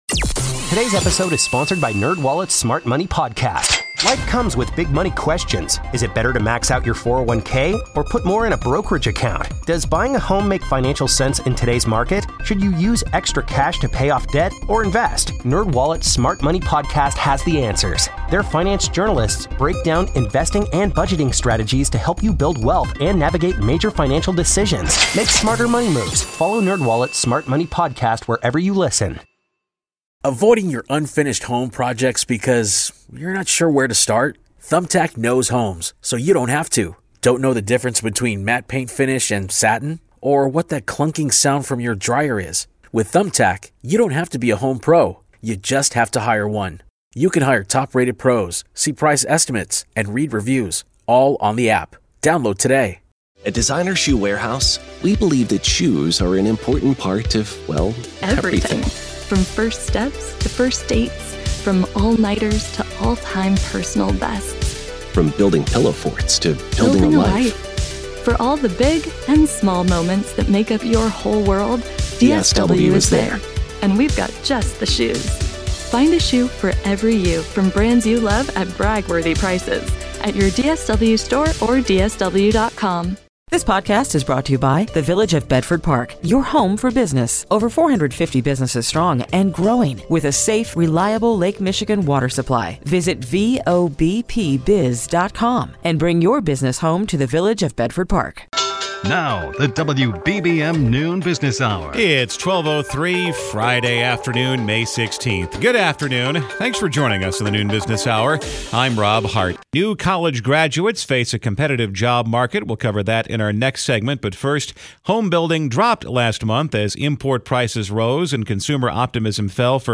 WBBM devotes an hour each weekday to discussion about the economy and financial markets with some of the top experts in the nation.